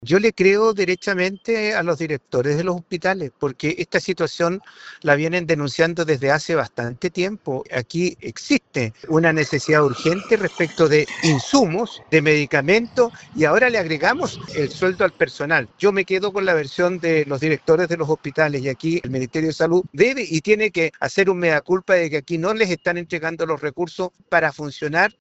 Respecto de esta diferencia entre lo señalado por los directores y el ministerio, el diputado Sergio Bobadilla (UDI) opinó que la versión real es la de los hospitales, porque “vienen denunciando esta situación desde hace bastante tiempo”.